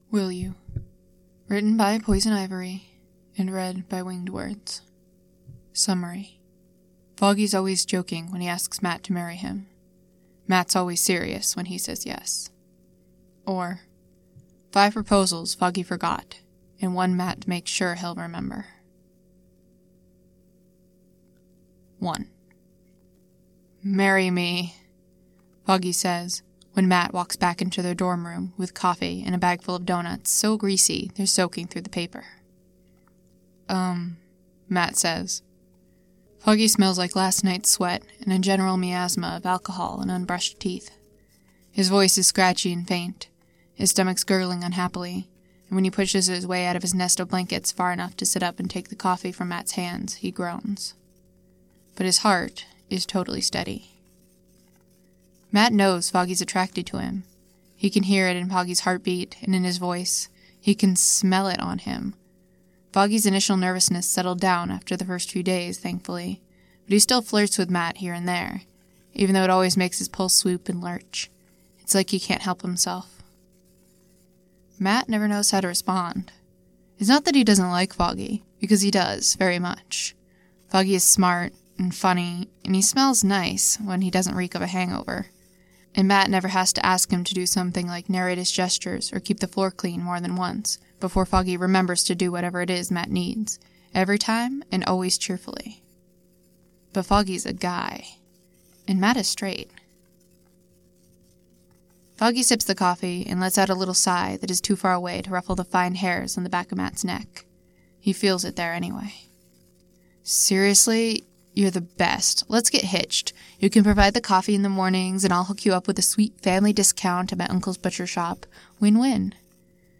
[Podfic]
Notes: Inspired by Will You by poisonivory This podfic was recorded for Podfic Broken Telephone 2017.